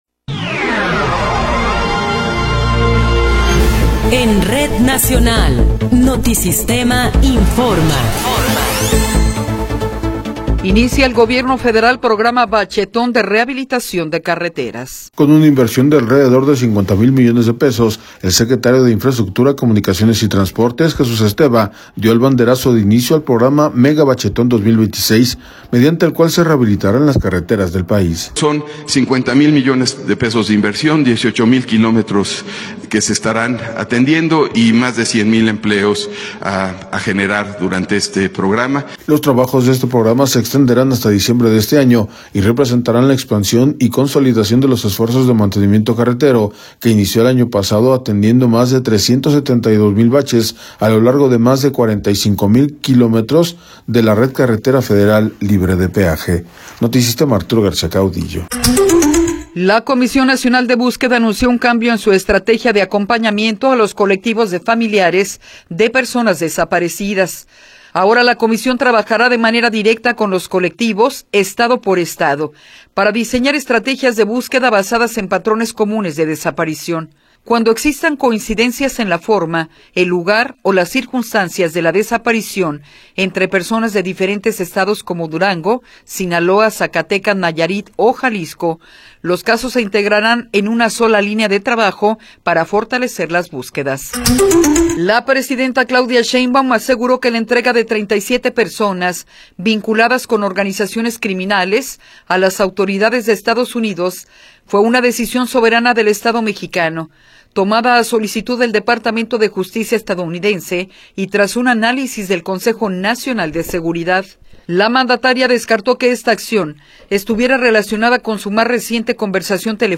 Noticiero 10 hrs. – 21 de Enero de 2026
Resumen informativo Notisistema, la mejor y más completa información cada hora en la hora.